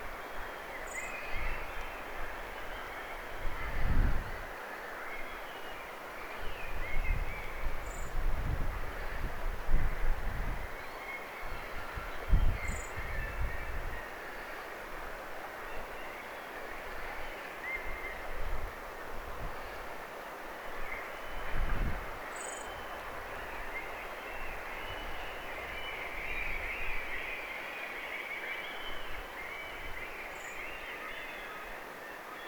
keltanokkarastaslinnun sirityksiä
keltanokkarastaslinnun_sirityksia.mp3